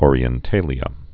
(ôrē-ĕn-tālē-ə, -tālyə)